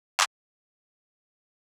clap 4.wav